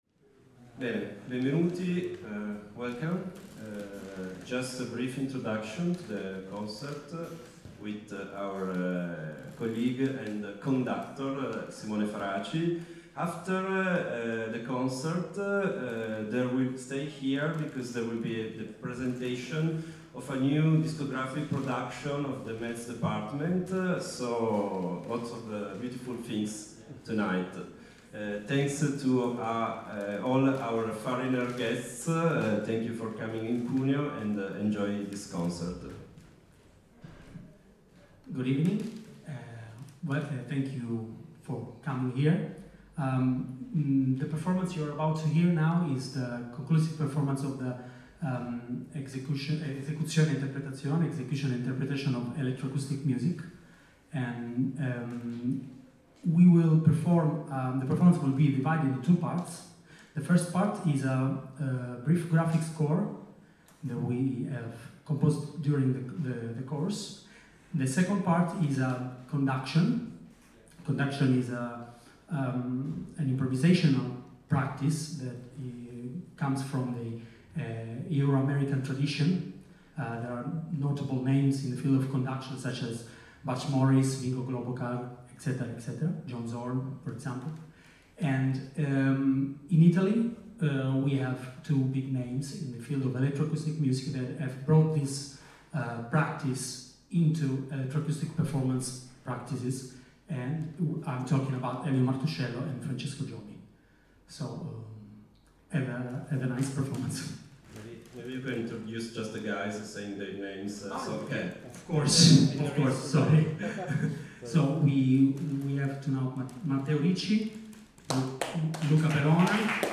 As part of the Europe B-AIR project, these days have taken place in Cuneo (italy), at the Conservatorio di Musica G.F.Ghedini from 5 to 8 October 2022.